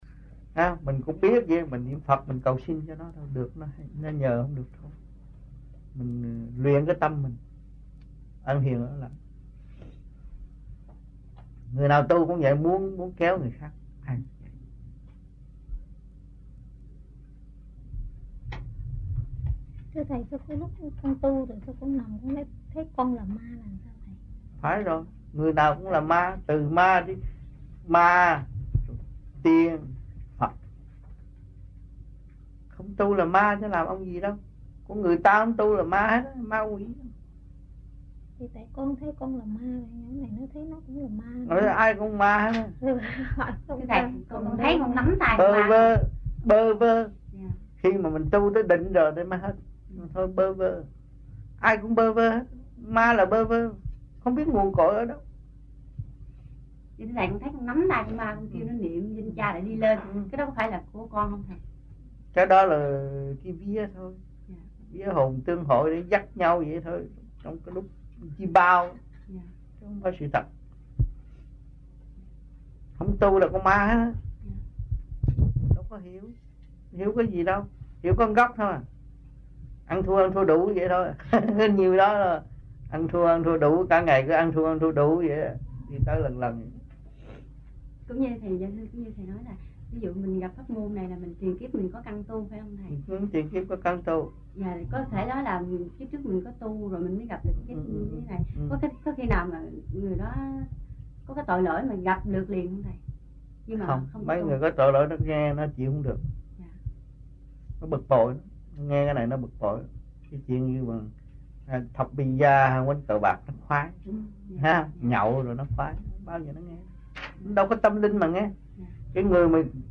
France Trong dịp : Sinh hoạt thiền đường >> wide display >> Downloads